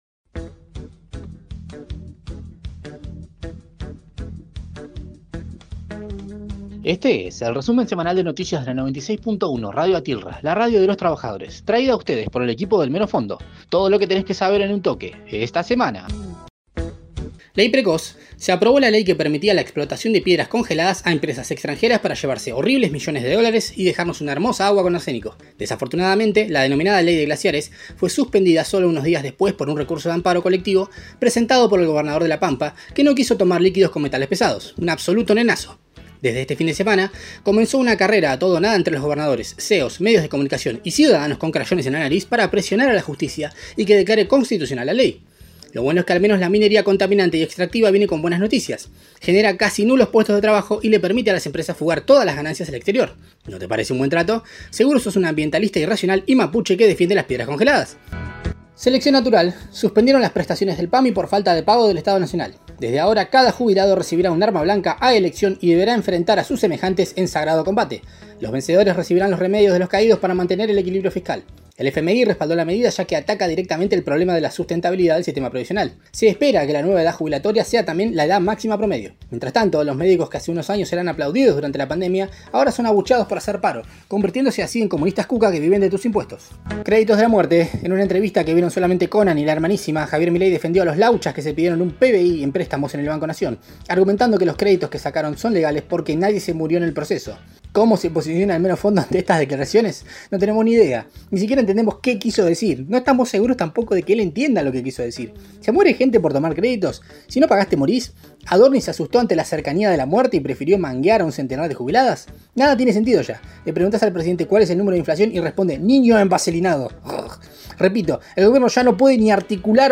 Flash Informativo